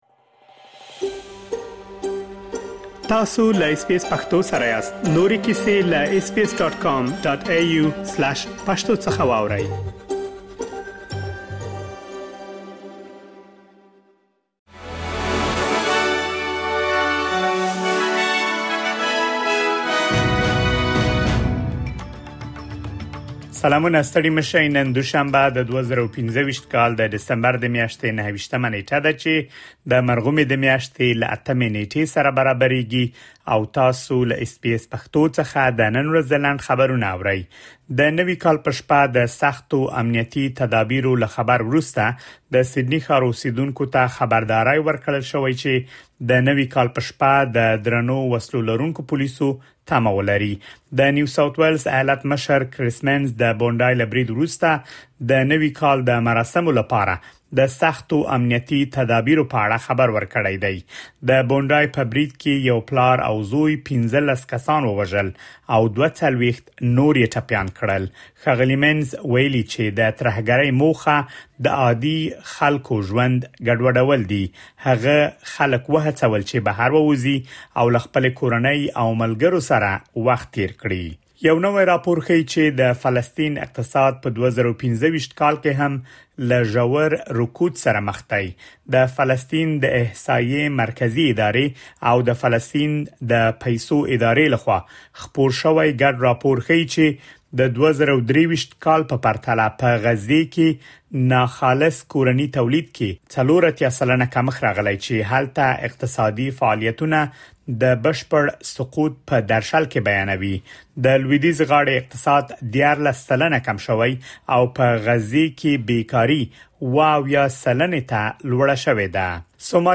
د اس بي اس پښتو د نن ورځې لنډ خبرونه |۲۹ ډسمبر ۲۰۲۵